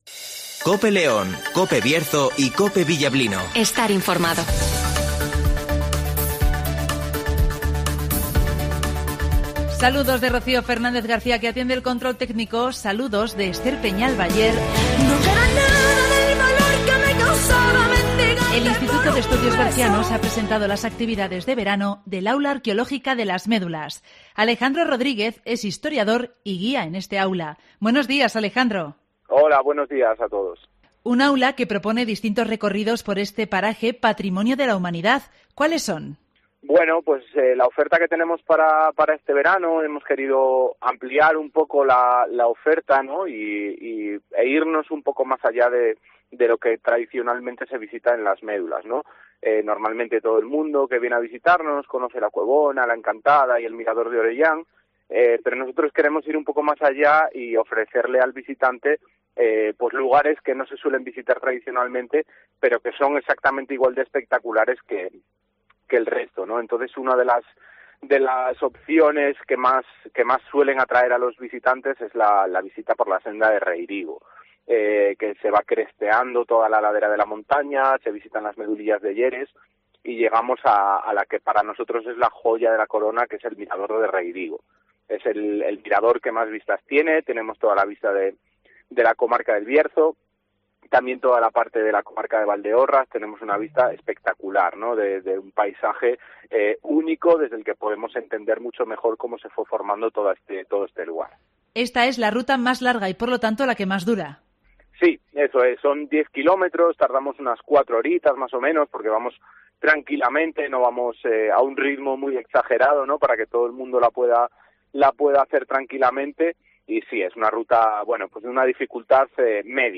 El Instituto de Estudios Bercianos organiza las actividades de verano del Aula Arqueológica de Las Médulas (Entrevista